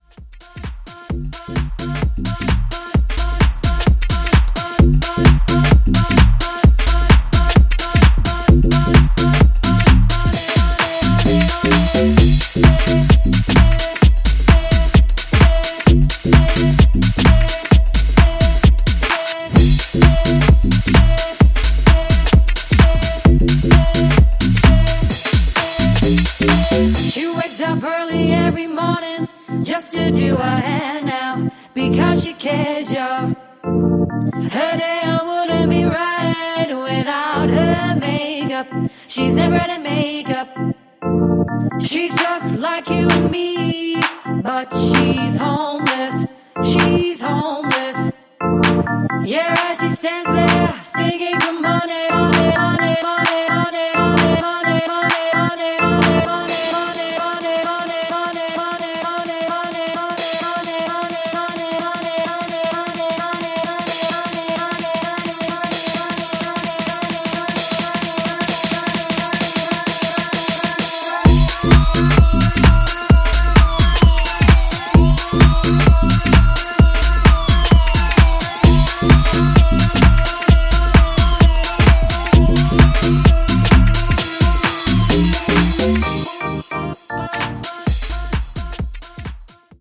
re-recorded vocals